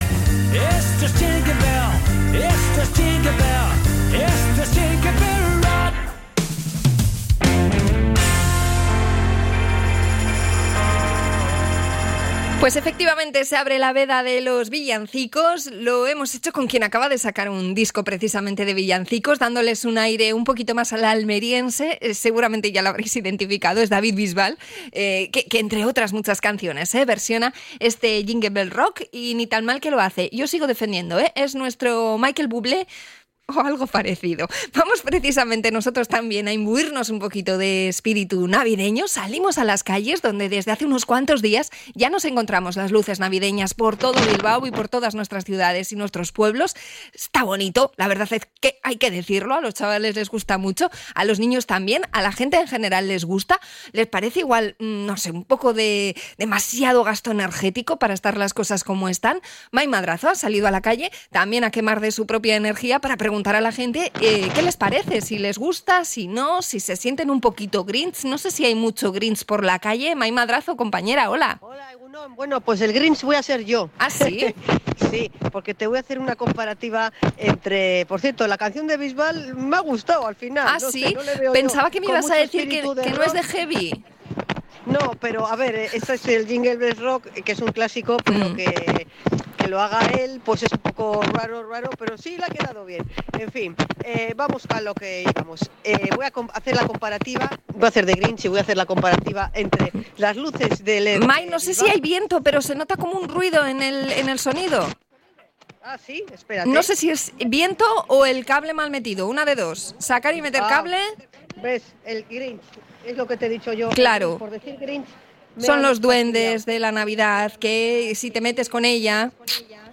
Salimos a la calle para sondear el primer fin de semana de espectáculo navideño en la villa